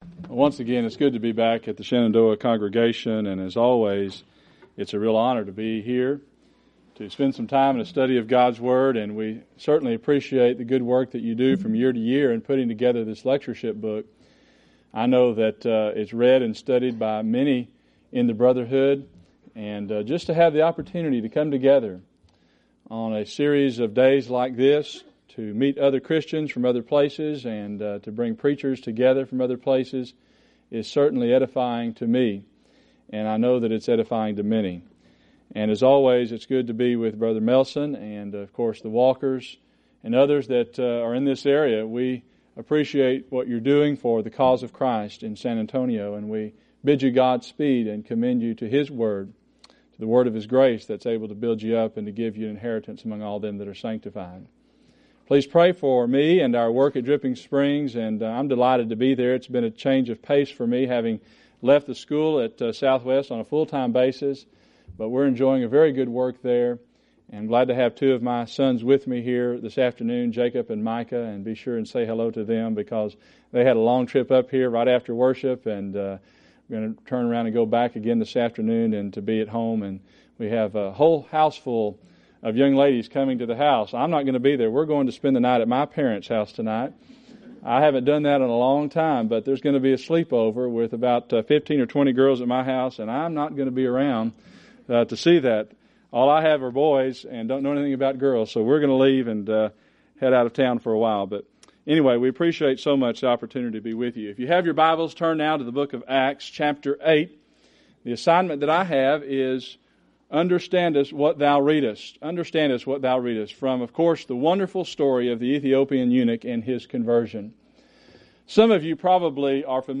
Series: Shenandoah Lectures Event: 2003 Annual Shenandoah Lectures Theme/Title: Great Questions in the Bible